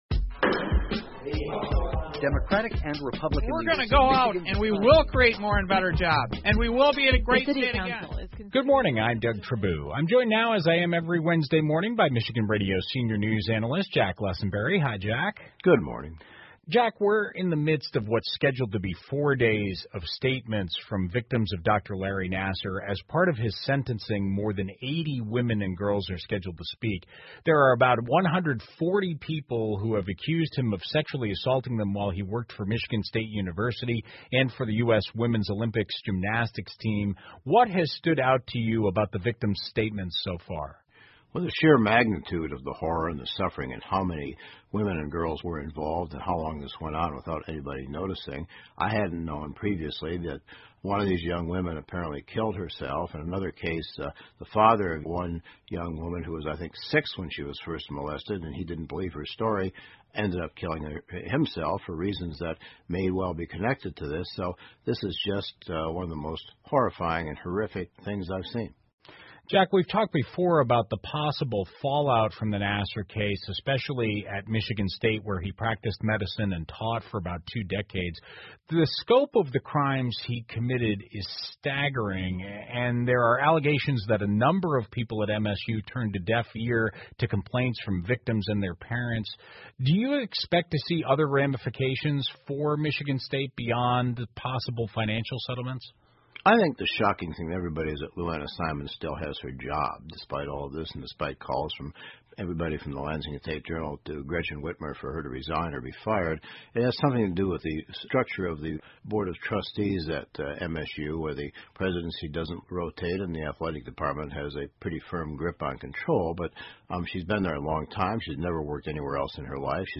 密歇根新闻广播 国家基本建设 听力文件下载—在线英语听力室